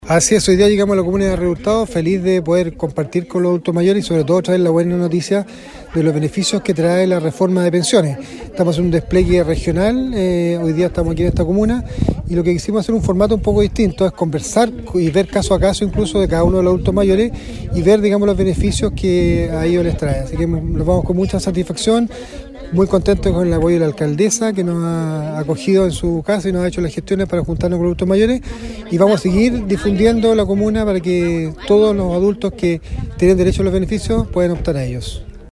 Por su parte, el seremi (s) del Trabajo, Juan Paulo Garrido, comentó la entrega de información a los vecinos de Tabaqueros.